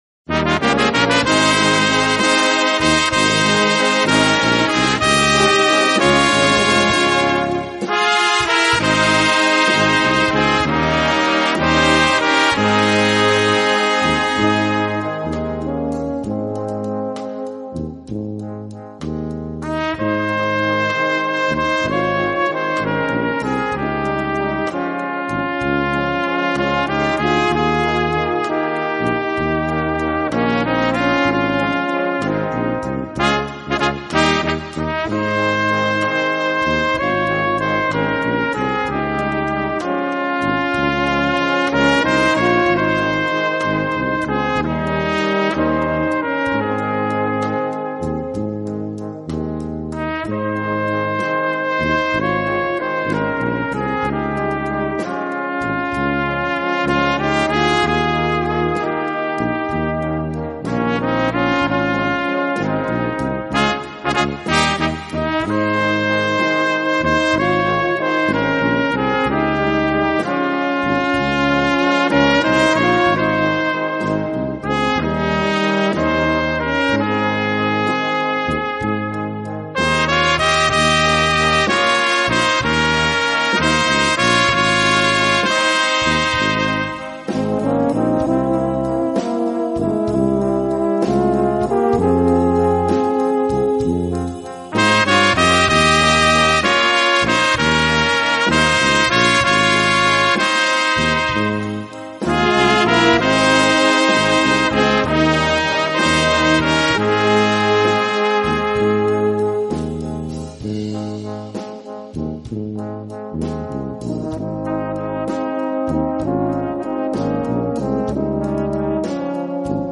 Besetzung: Kleine Blasmusik-Besetzung